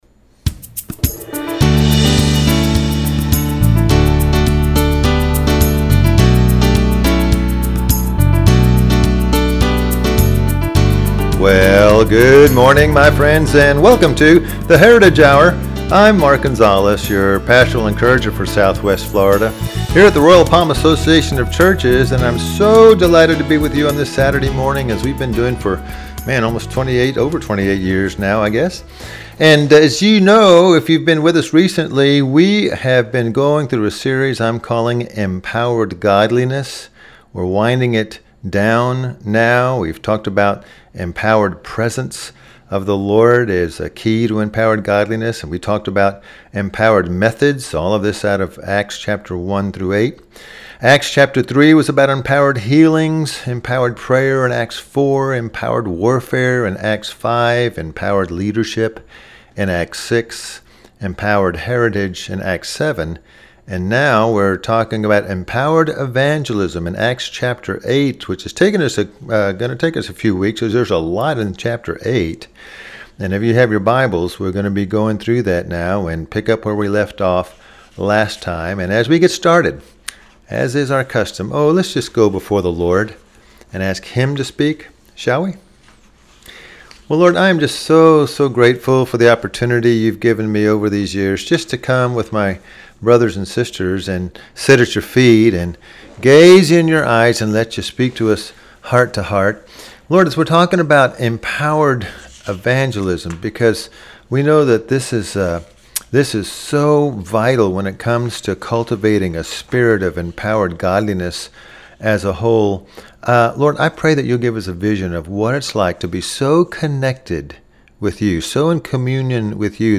Radio Message